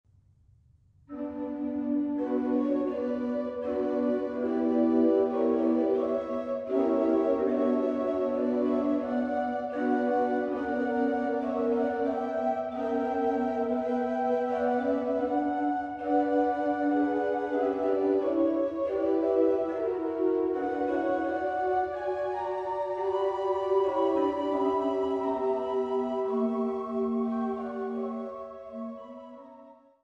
Praeludium B-Dur